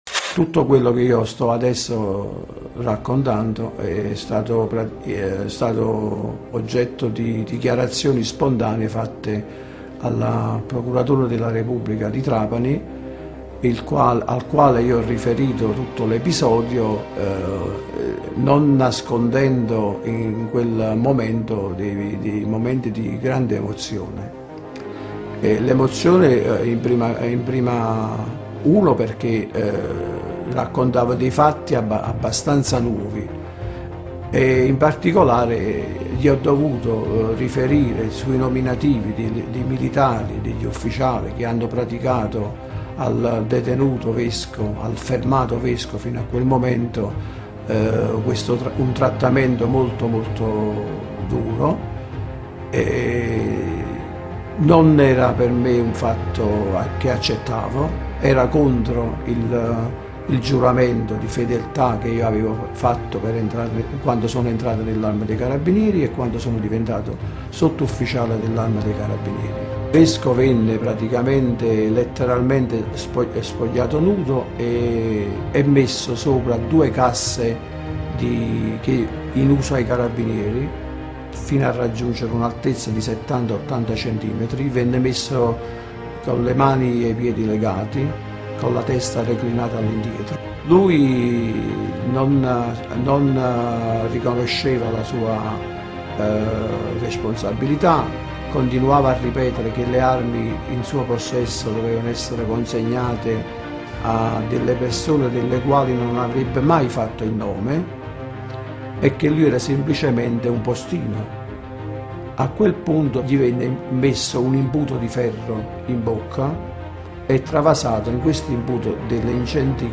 Quelle che ascolteremo di seguito sono le voci tratte da un documento filmato che è facilmente reperibile in rete.
Derivano anche da trasmissioni radiofoniche e televisive, come ad esempio Blu Notte e La storia siamo noi.